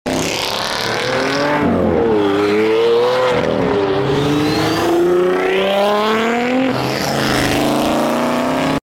Aventador SV, 2XXX HP GTR, sound effects free download
Aventador SV, 2XXX HP GTR, and Twin Turbo Camaro leaving Dayton Cars and Coffee